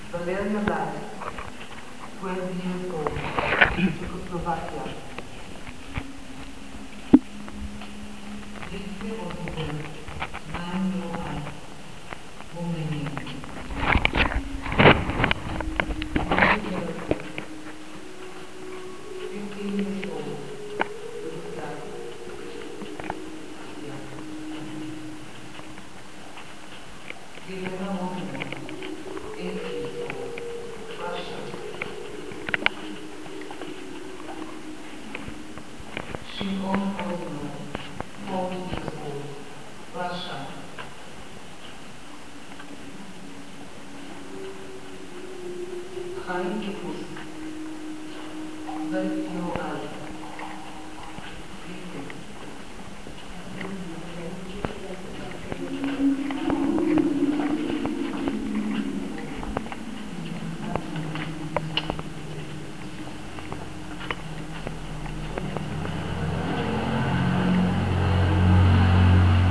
館内は真っ暗で周りは全て鏡になっており、ロウソクが灯るなか、スピーカーから流れてくるのは、犠牲になった子どもたちの名前だ。
音の世界だった。